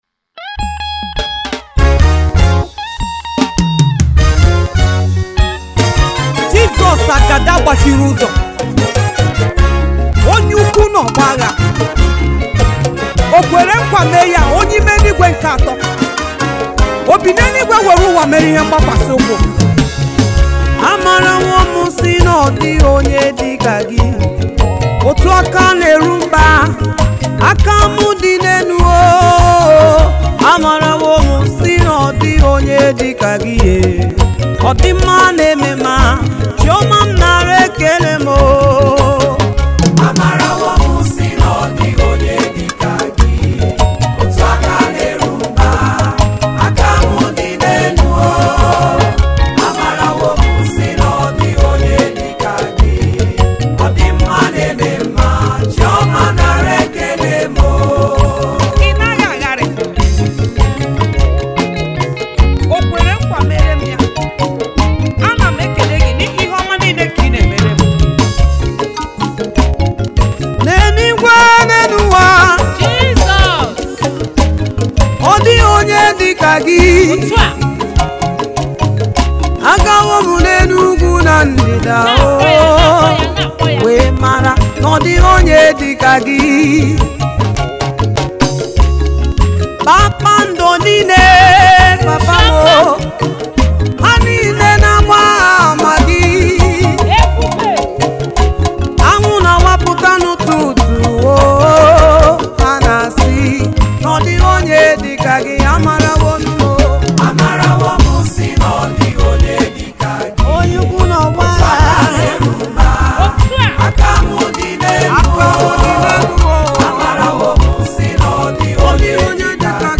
a sensational and inspirational gospel singer